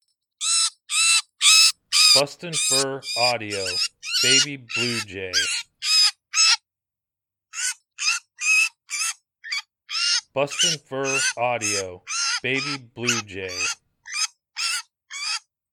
BFA Baby Blue Jay
Baby Blue Jay in distress, good sound for calling all predators.
BFA Baby Blue Jay Sample.mp3